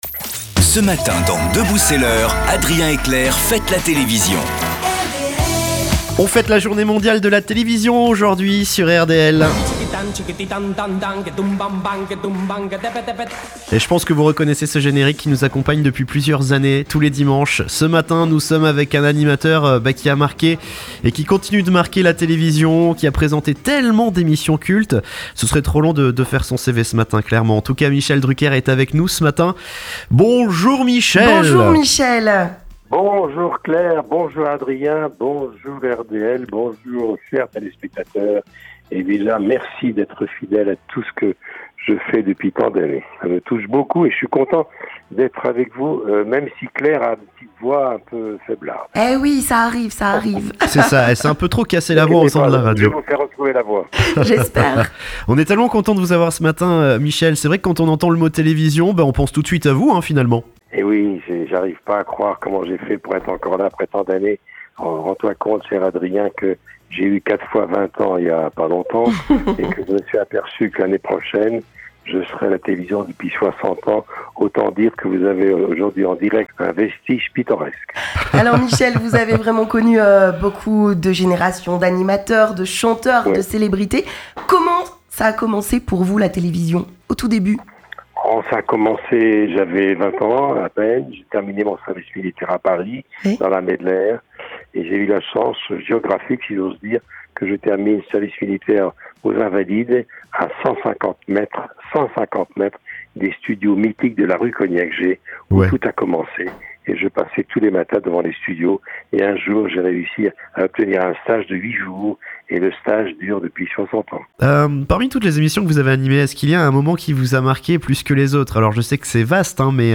Michel Drucker en interview sur RDL !